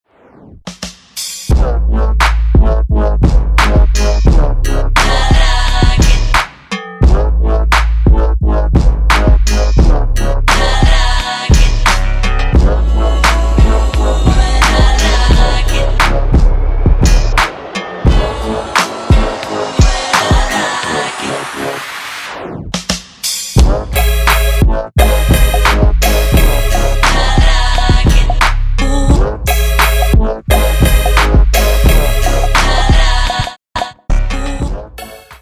• Качество: 320, Stereo
спокойные